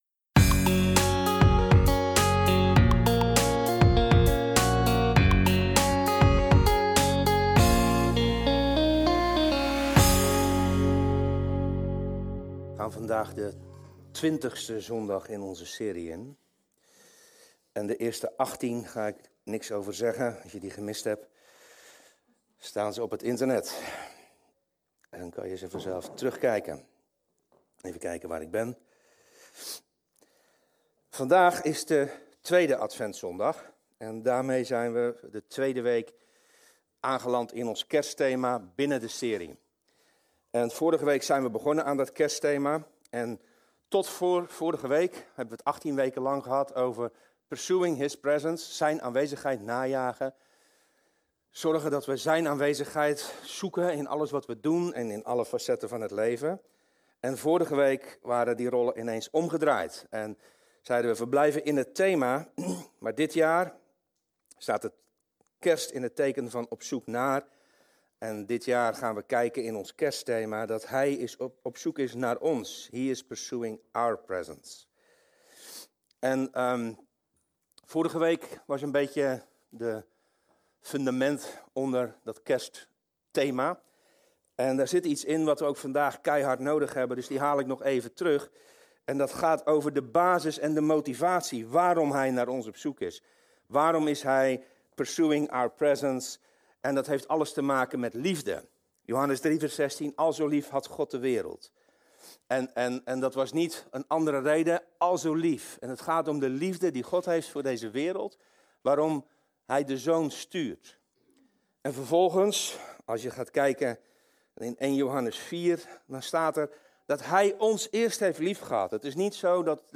Dit kanaal bevat de preken zoals deze op zondagochtend in onze gemeente worden gehouden.